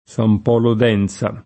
Sam p0lo d $nZa] (E.-R.), San Polo in Chianti [